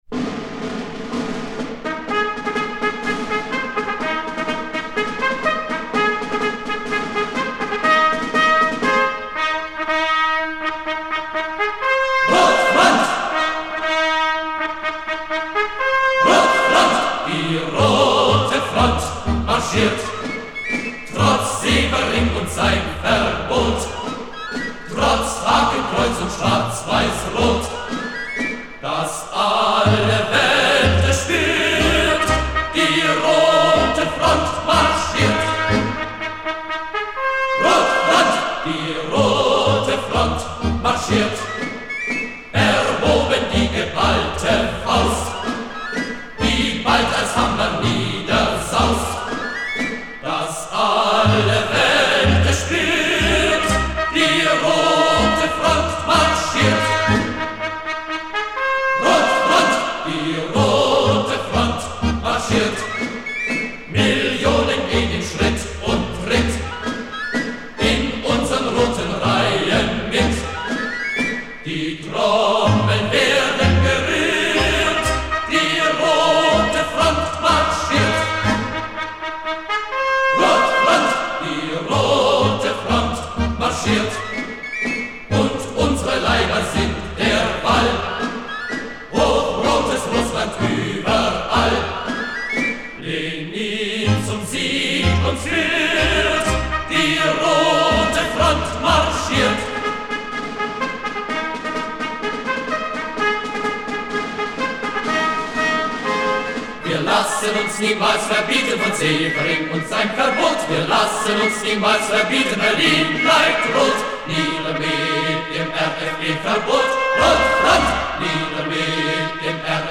выходная песня агитбригады